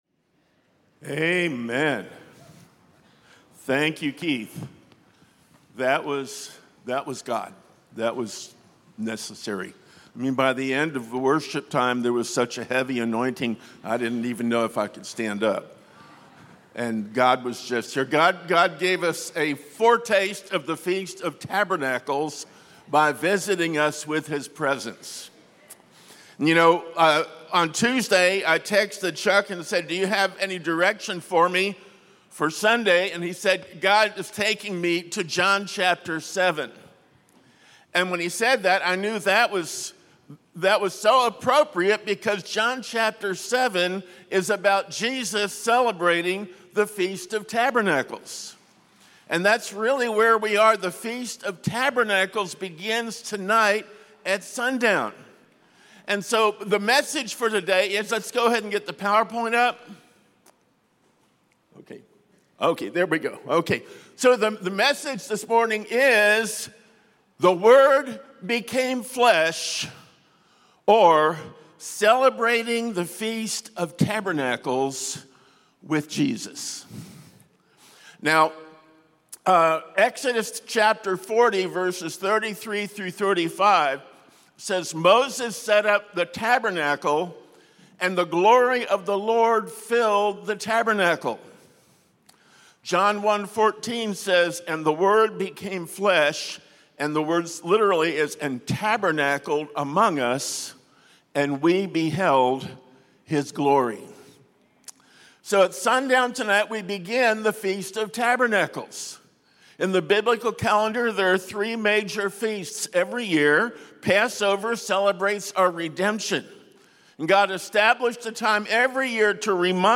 Sunday Celebration Service